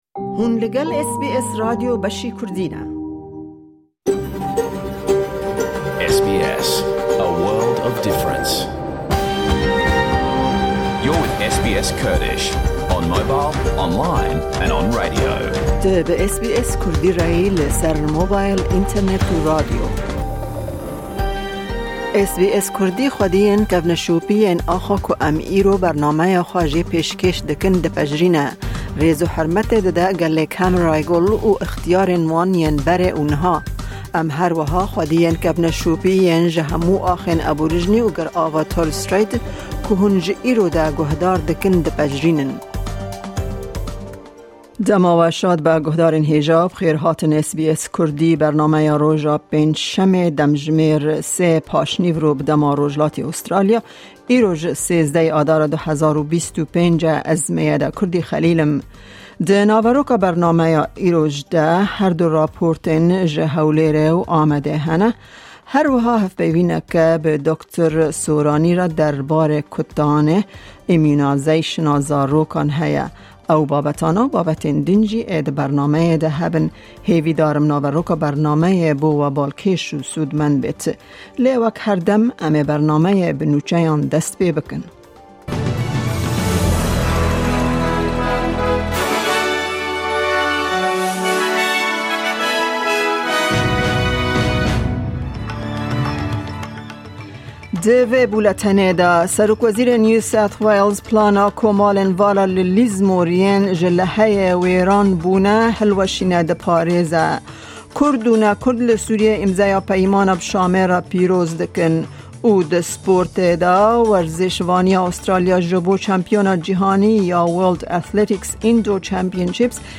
Guhdare tevahiya bernameya SBS Kurdî ya roja Pêncşeme bike. Hevpeyvî, raportên ji Hewlêr û Amed û gelek babetên din.